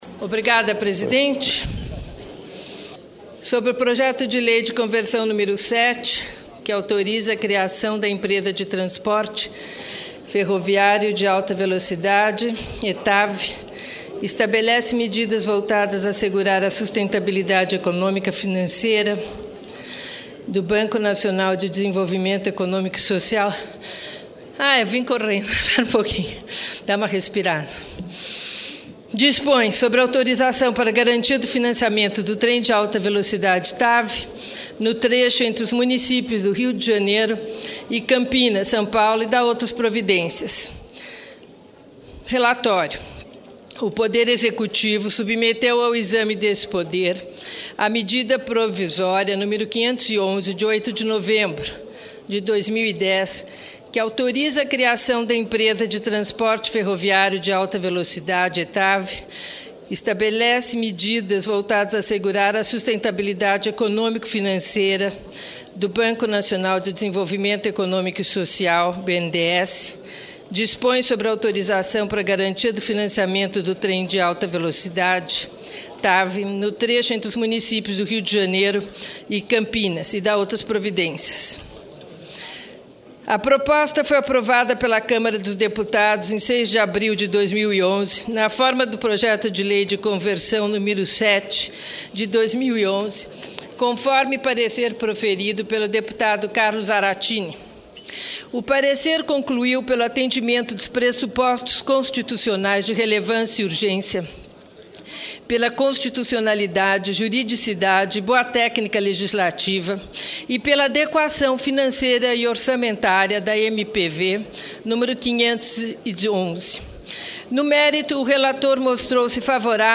Parecer da relatora Marta Suplicy sobre a MP do trem-bala